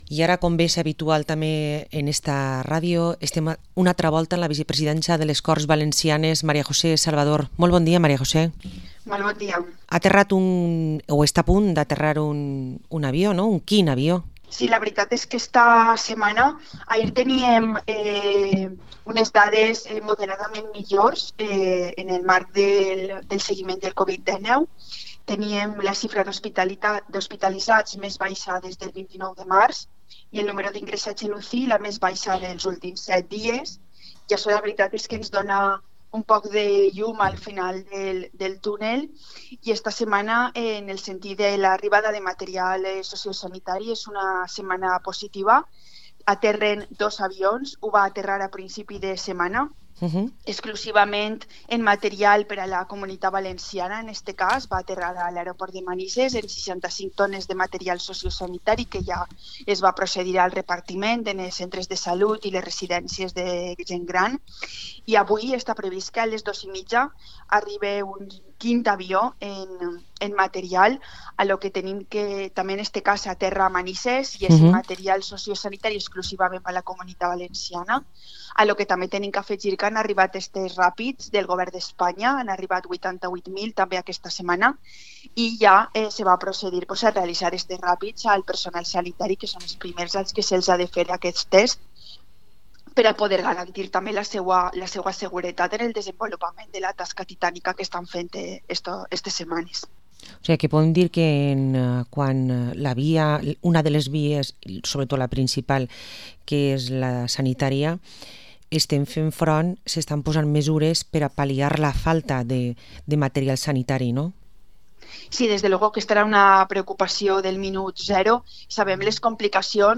Entrevista a María José Salvador, vicepresidenta de las Cortes Valencianas